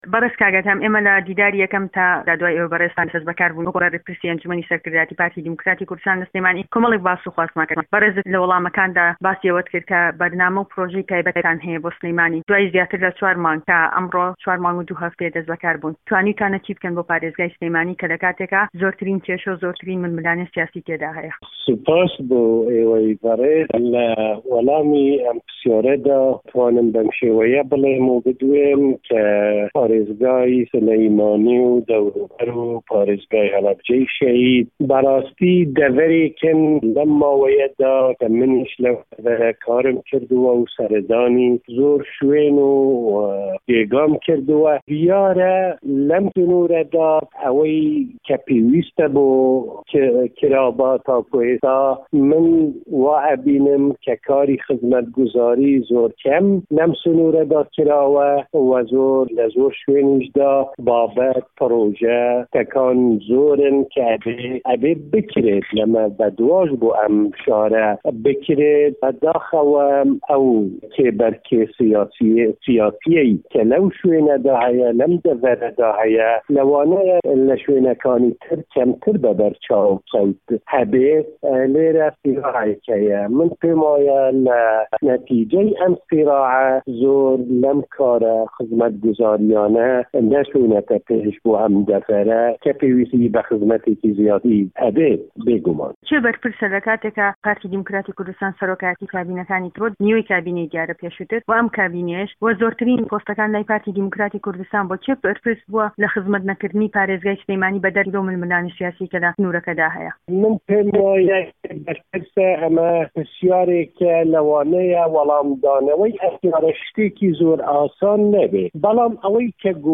ئه‌دهه‌م بارزانی به‌رپرسی ئه‌نجومه‌نی سه‌رکردایه‌تی پارتی دیموکراتی کوردستان له‌ میانی وتووێژێکی تایبه‌تدا له‌گه‌ڵ به‌شی کوردی ده‌نگی ئه‌مەریکا باس له‌ چه‌ند ته‌وه‌رێک و پرسی ئێستای کوردستان و سلێمانی ده‌کات